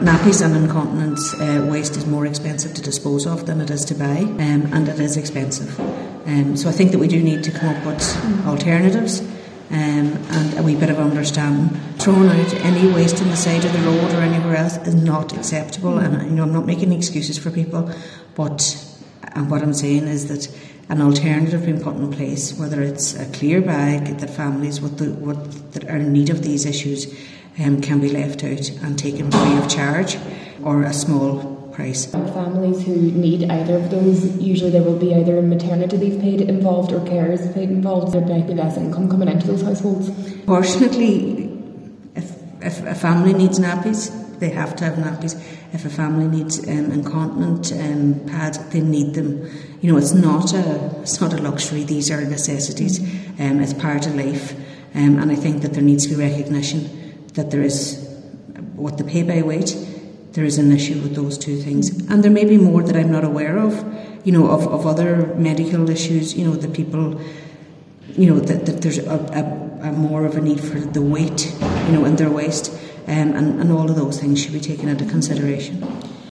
Cllr Marie Therese Gallagher told a meeting of Glenties Municipal District that it needs to be examined, as families are now paying more to dispose of these products that to buy them.